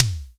Index of /90_sSampleCDs/Roland - Rhythm Section/DRM_Analog Drums/SNR_Analog Snrs
SNR FLOOR 06.wav